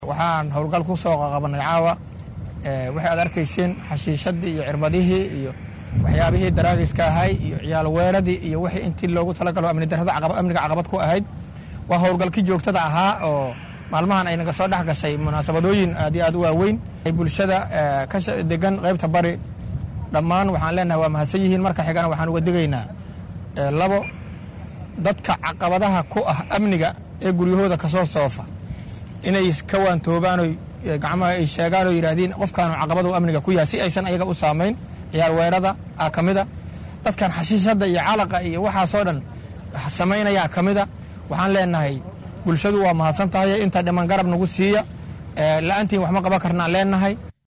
Taliyaha qaybta Bari ee Booliska Gobolka Banaadir Cali Calasow Xasan oo Warbaahinta la hadlay ayaa sheegay in howlgalkan uu yahay mid si joogto ah loo sameyn doono, isaga oo digniin u diray dadka ka Ganacsada waxyaabaha Maanka dooriya.